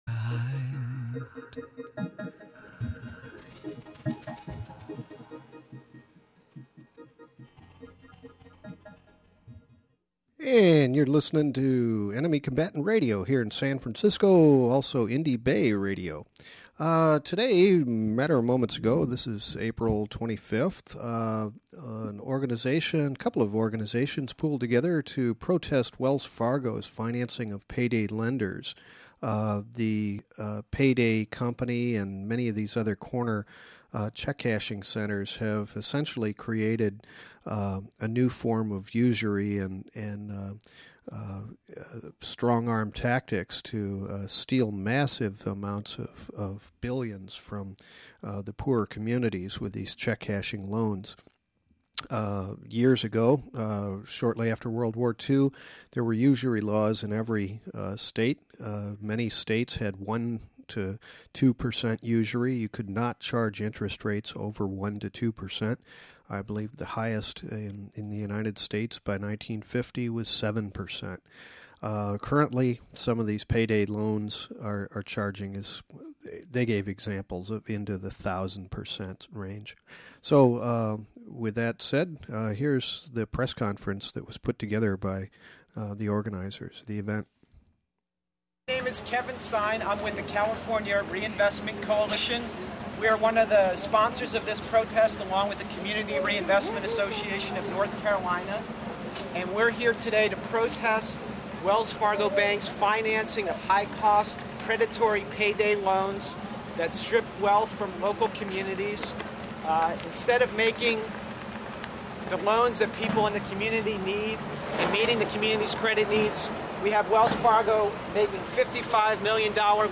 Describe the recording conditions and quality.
Demonstration coverage of Wells Fargo's complicity in predatory loan practices with PayDay loansharks